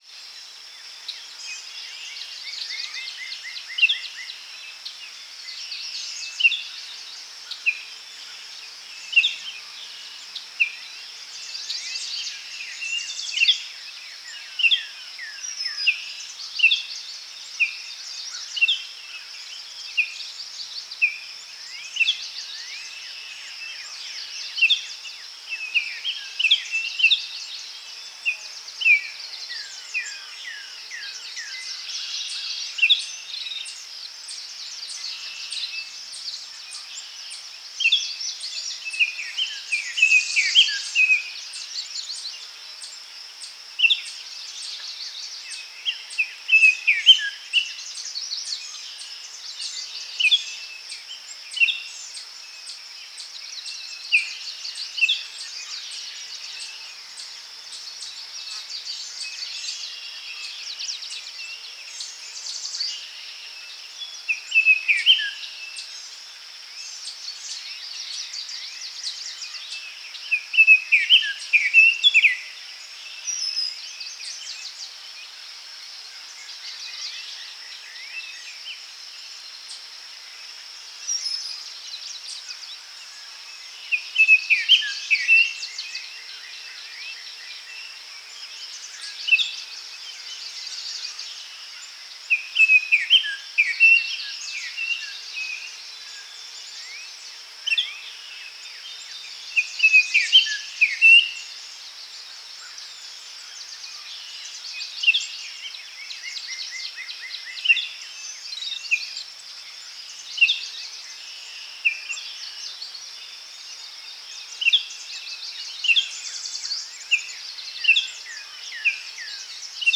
mandrake foundry13data/Data/modules/soundfxlibrary/Nature/Loops/Forest Day
forest-day-3.mp3